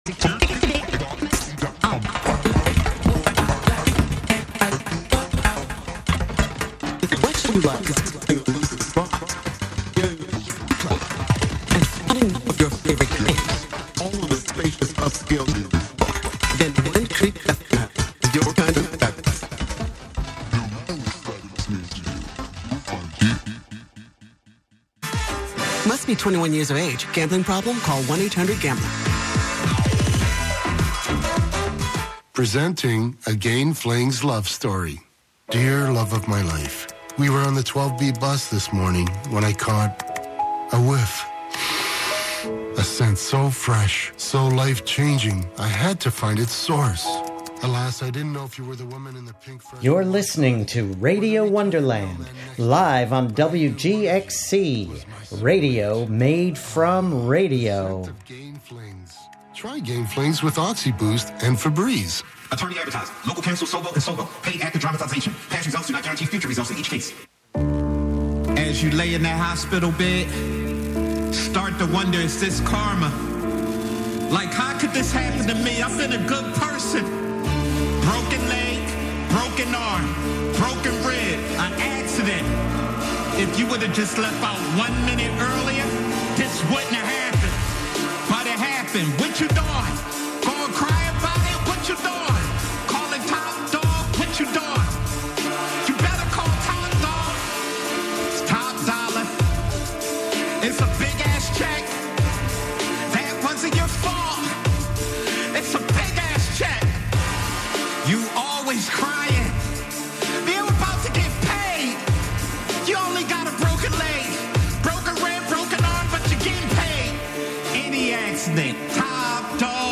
Live from Brooklyn, New York,